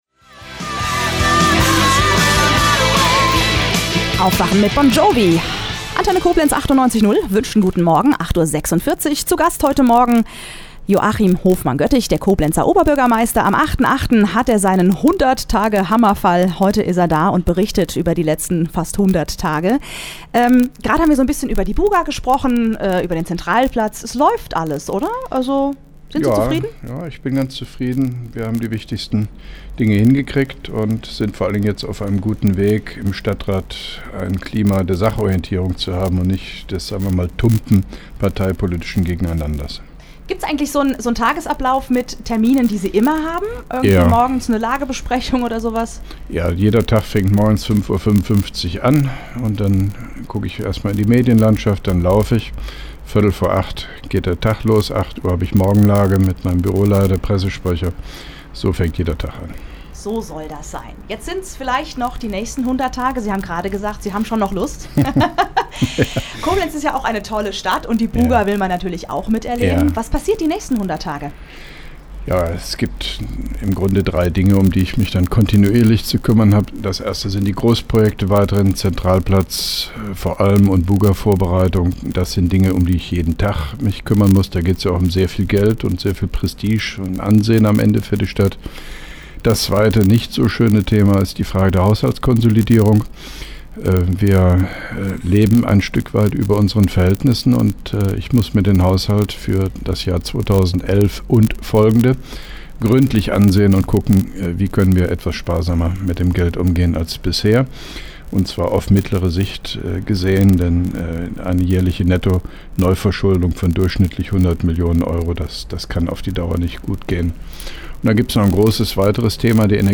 (3) Rundfunk-Interview mit dem Koblenzer OB Joachim Hofmann-Göttig zu den ersten 100 Tagen als Oberbürgermeister am 08.08.2010: